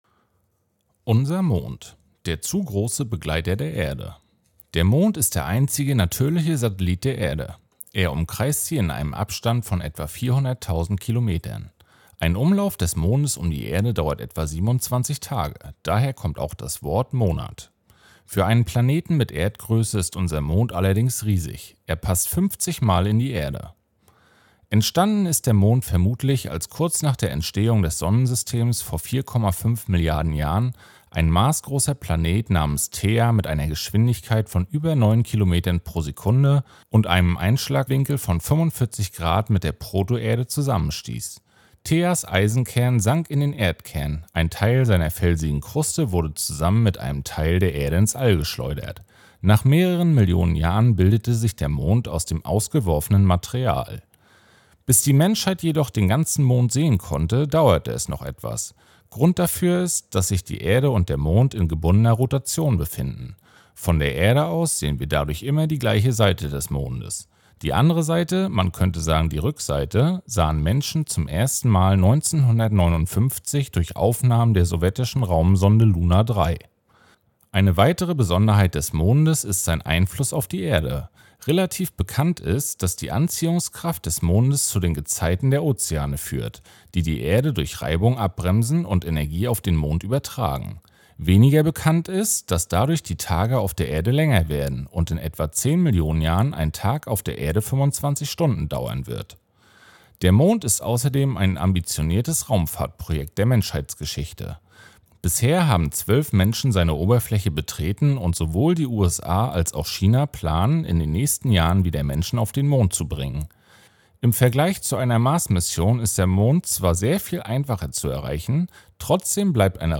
Audioguide
Promovierende vom Institut für Physik der Uni Rostock geben spannende Einblicke in ihre Forschung und erklären das Weltall.